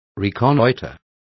Complete with pronunciation of the translation of reconnoiter.